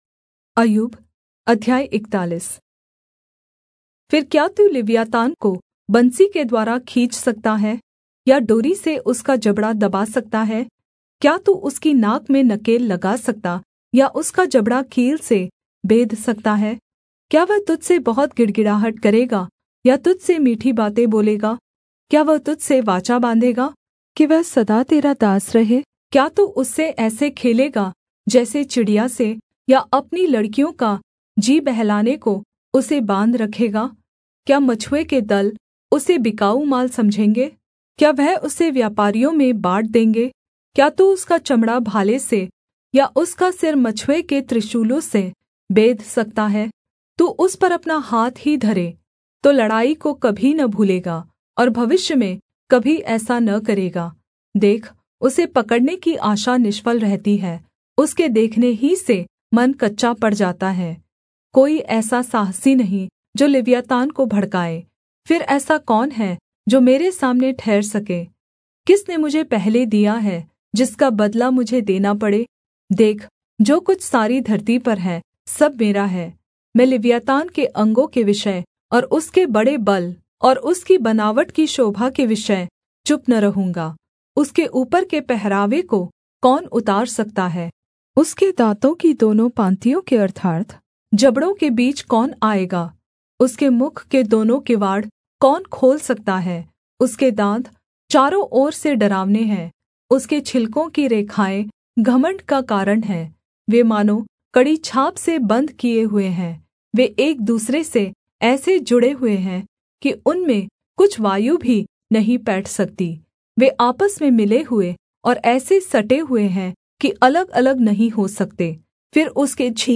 Hindi Audio Bible - Job 12 in Irvhi bible version